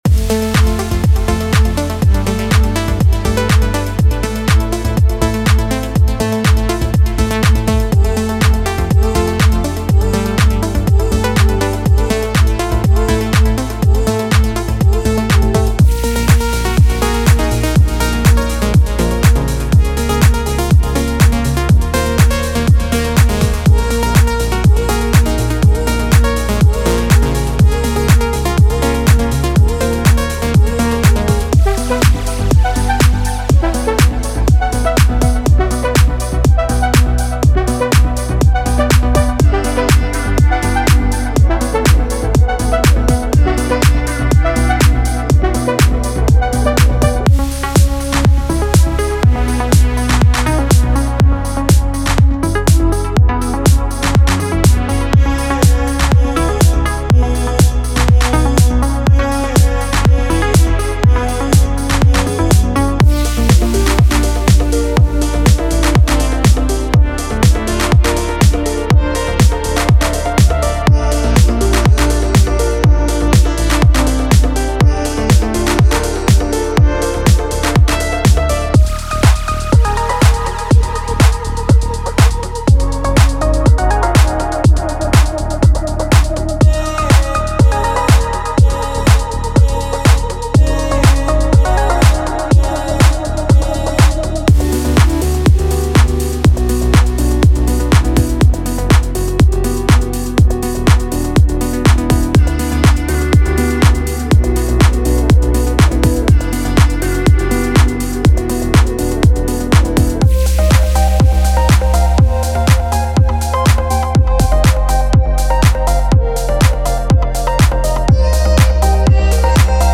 This meticulously crafted loop pack is designed to infuse your tracks with the warm, organic vibes that define the house music genre.
16 Bass Loops: Deep, groovy basslines that lay the perfect foundation for any house track.
30 Drum Loops: Crisp, punchy drum loops that drive the rhythm and energy of your productions.
10 FX Loops: Atmospheric and dynamic effects to add that extra sparkle and dimension.
27 Synth Loops: Lush, melodic synth loops that inspire creativity and add a unique character to your music.
5 Vox Loops: Soulful vocal snippets that bring a human touch to your compositions.